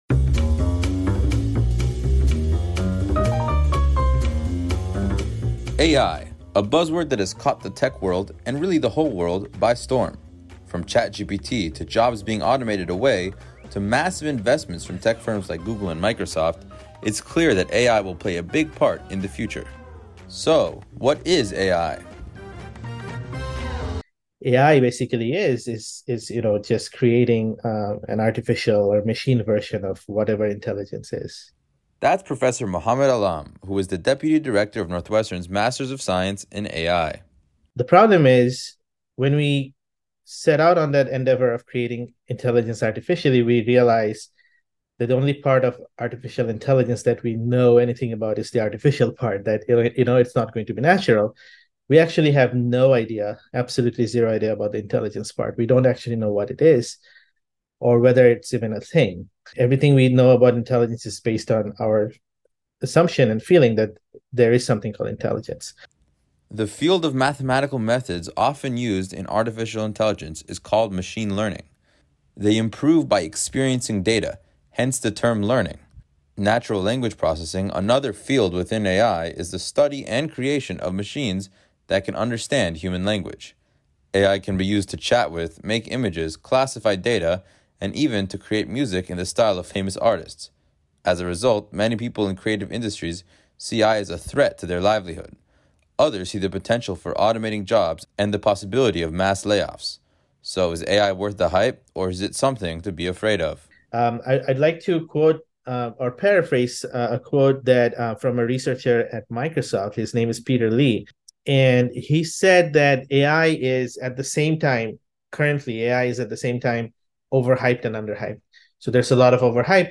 This story originally aired as part of our A.I. Special Broadcast.